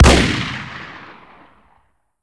wpn_huntingshotgun.wav